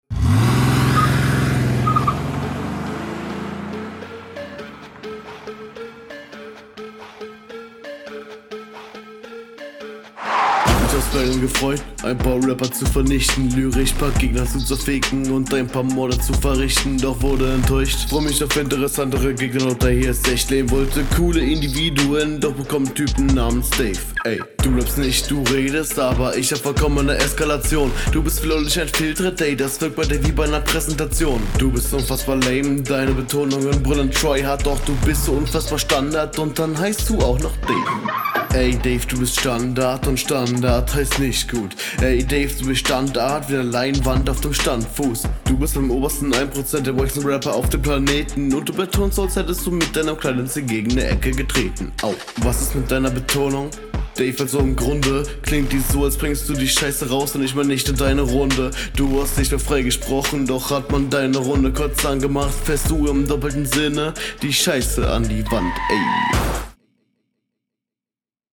super patterns, aber routine fehlt leider, stimmeinsatz sehr drucklos